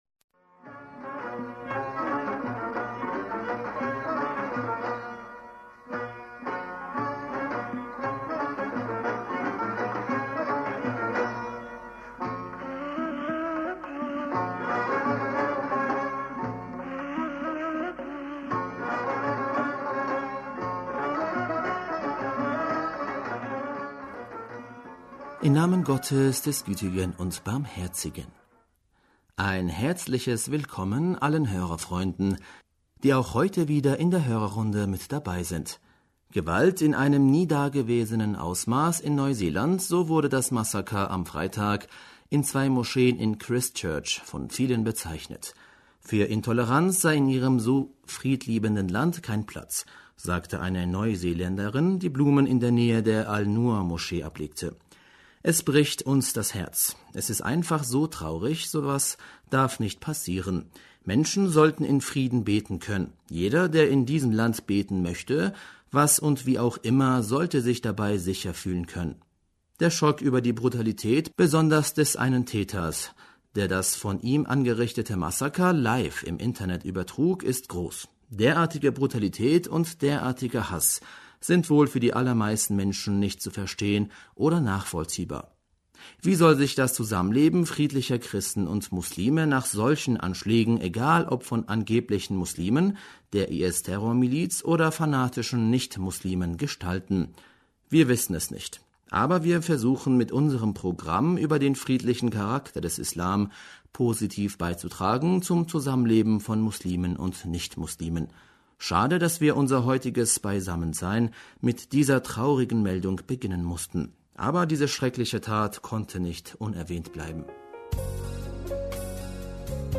Hörerpostsendung am 17.März 2019 - Bismillaher rahmaner rahim - Ein herzliches Willkommen allen Hörerfreunden, die auch heute wieder in der Hörerr...